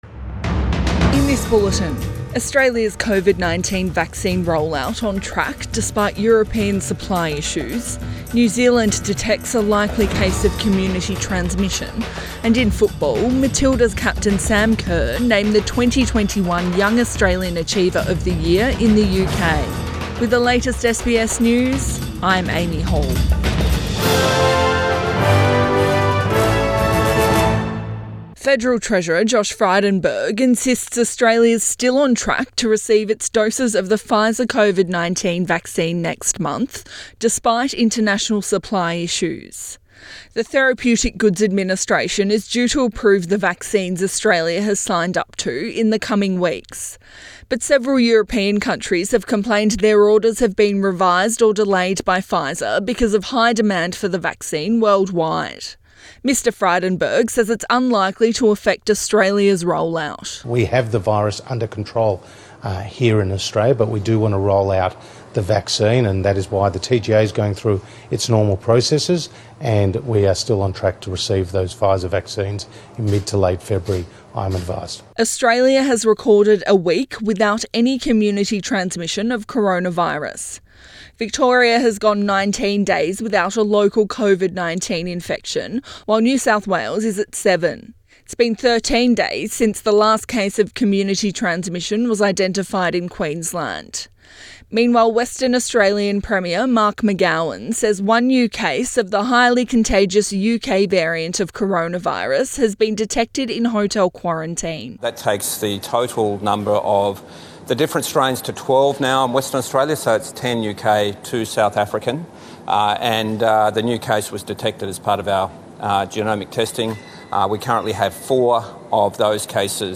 PM bulletin 24 January 2021